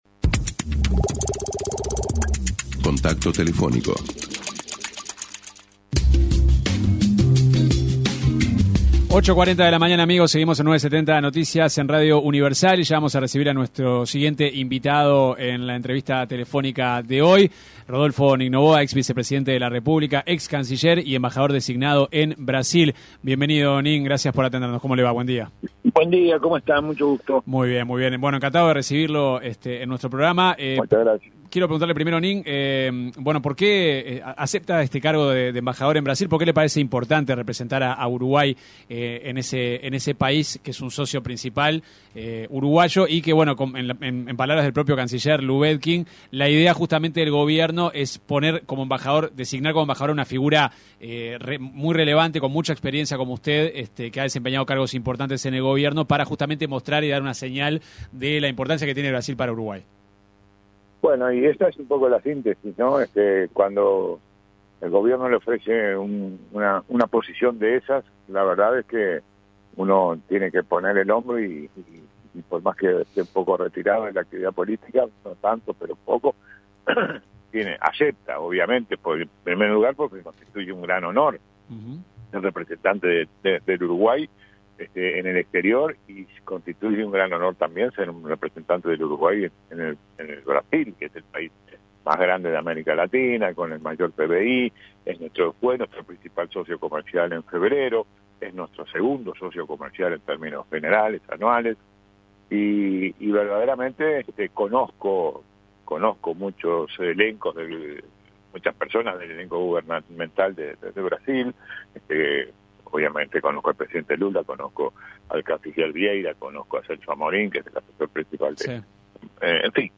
Escuche la entrevista completa aquí: El designado embajador de Uruguay en Brasil y excanciller de la República, Rodolfo Nin Novoa, se refirió en una entrevista con 970 Noticias, a su visión sobre el Mercosur y cómo ve todo el contexto en la región.